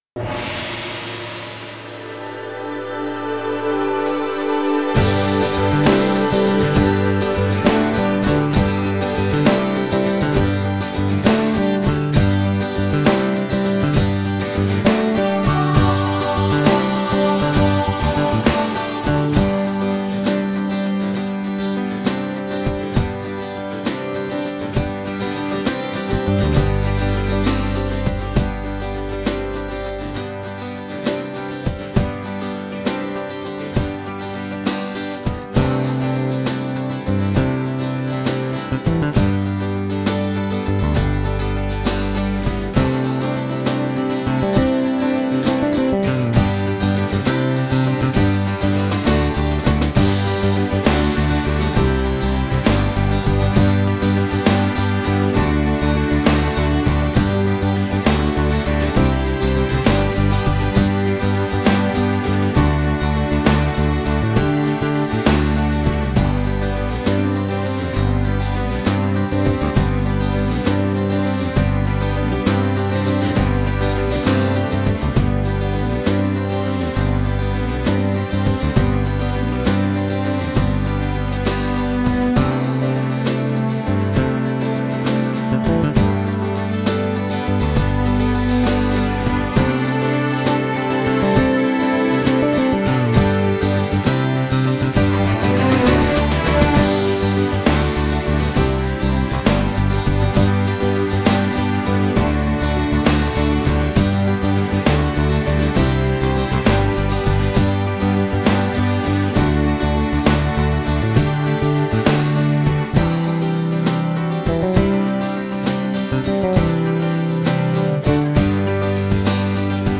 Free Christmas Carols Backing Tracks